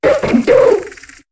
Cri de Miamiasme dans Pokémon HOME.